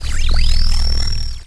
sci-fi_electric_pulse_hum_08.wav